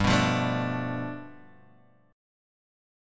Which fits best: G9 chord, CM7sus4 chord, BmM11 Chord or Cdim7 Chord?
G9 chord